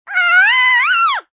babyscream4.ogg